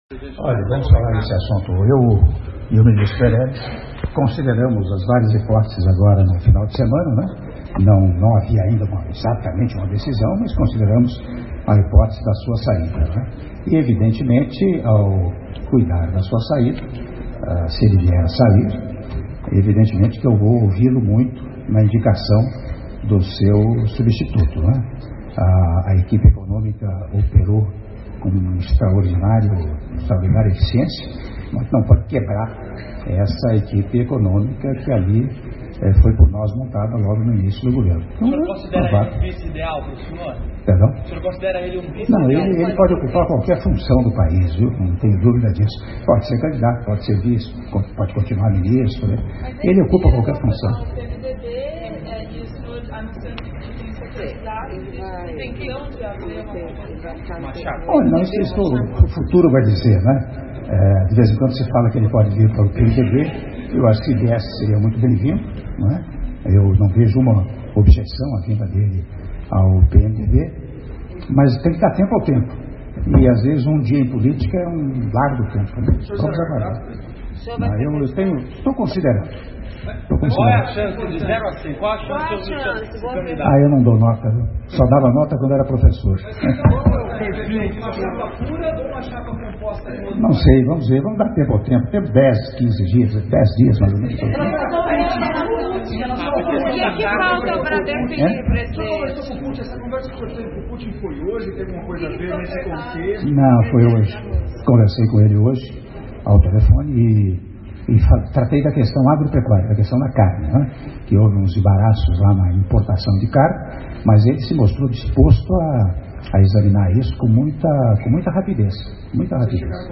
Áudio da entrevista coletiva concedida pelo Presidente da República, Michel Temer, após Reunião FECOMERCIO-SP - (02min13s) - São Paulo/SP